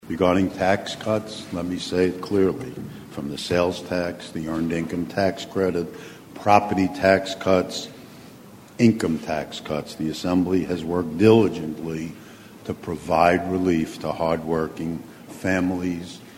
The Remarks Of Speaker Sheldon Silver
Post "State Of The State" Press Conference
Assembly Parlor